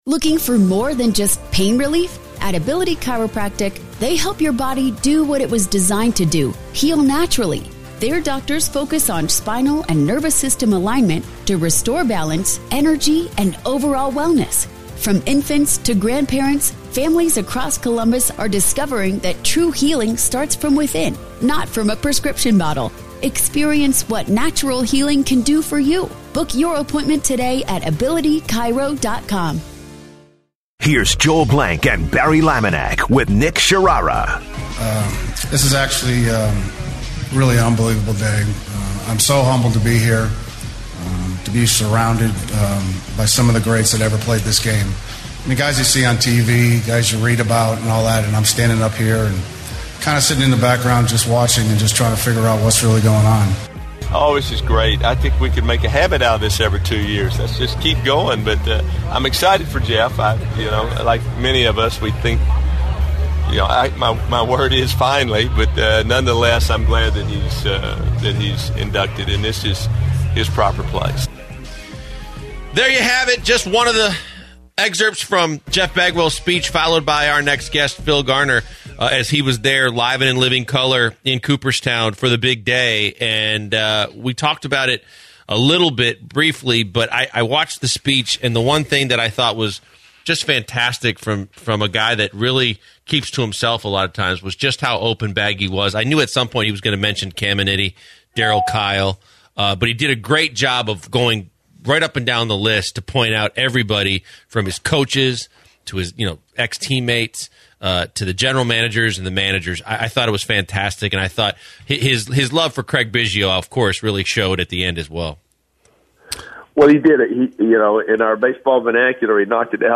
Former Astros manager, Phil Garner, joined the Usual Suspects and talked about Jeff Bagwell's HOF ceremony, and the Astros trading for Francisco Liriano.